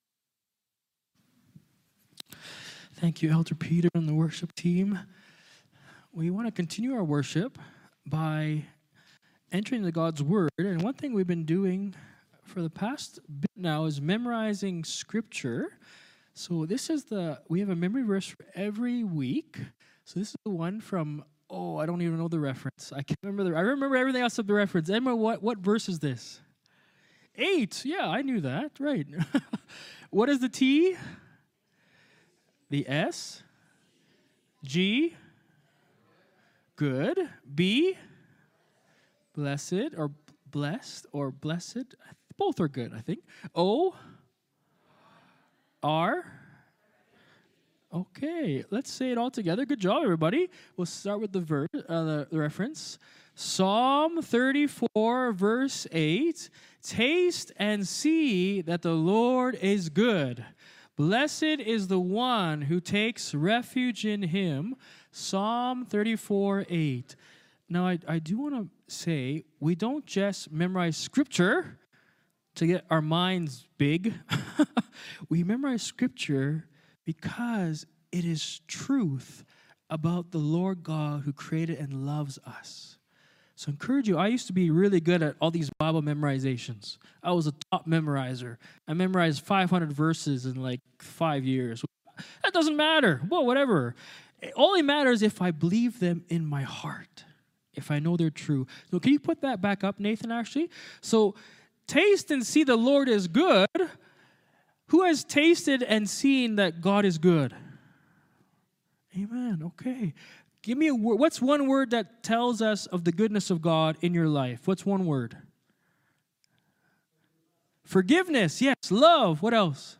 Sermons | mosaicHouse
Sermon Notes